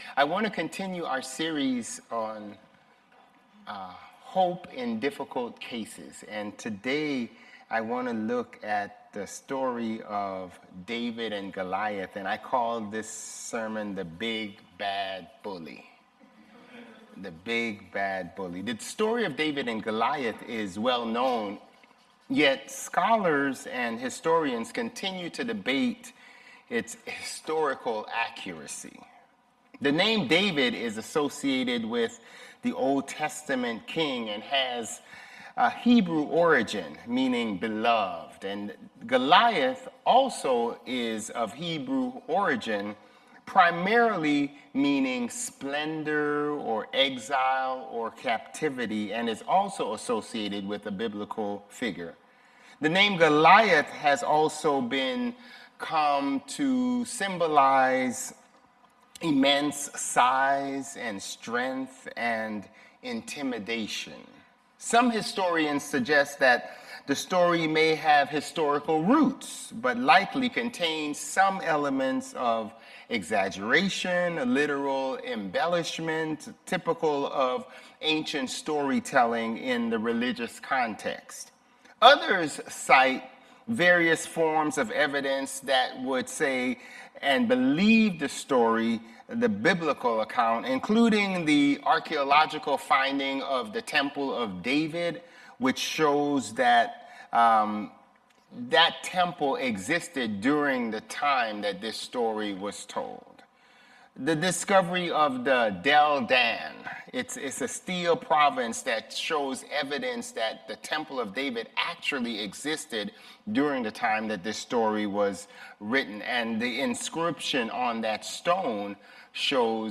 August 17 Worship